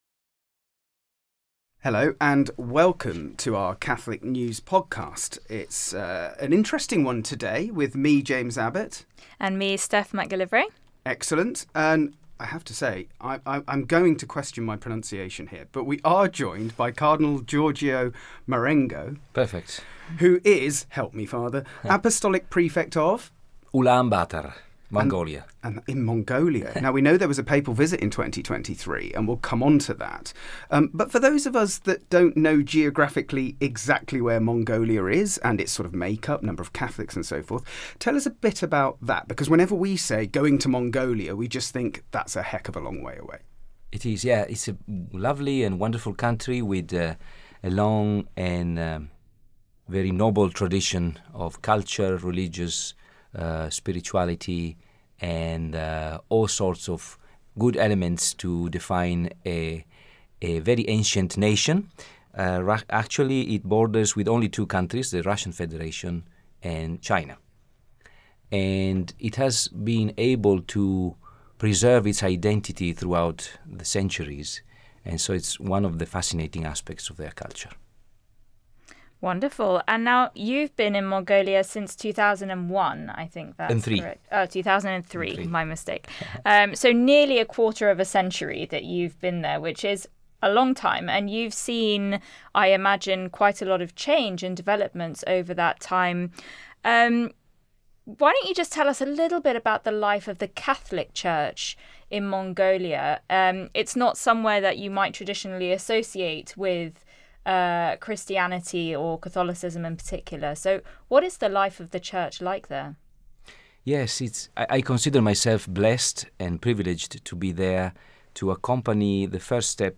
In today’s Catholic News podcast, we are joined by His Eminence Cardinal Giorgio Marengo, Apostolic Prefect of Ulaanbaatar, Mongolia. The Cardinal has been visiting the UK this week with Missio, the Pope’s charity for world mission.